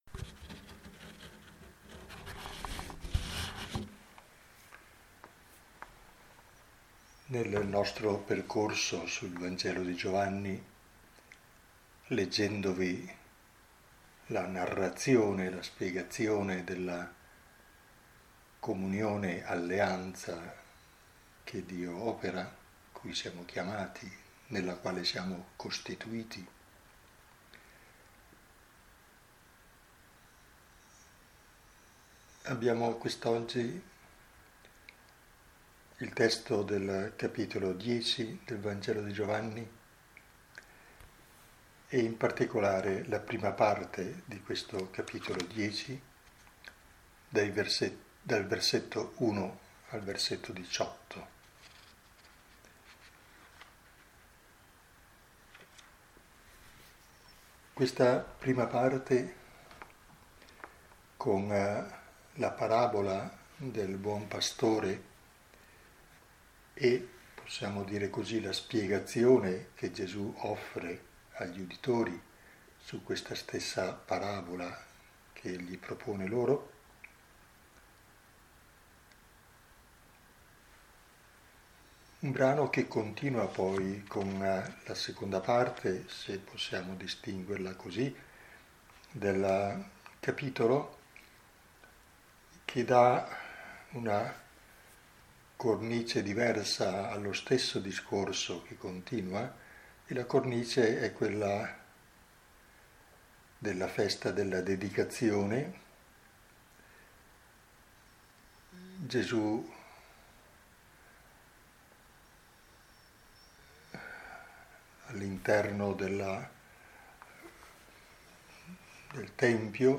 Lectio 8 – 17 maggio 2020 – Antonianum – Padova